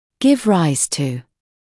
[gɪv raɪz tuː][гив райз туː]вызывать; приводить к возникновению